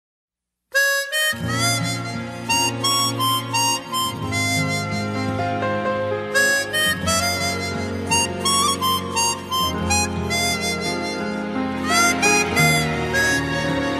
알림음 8_감수성.ogg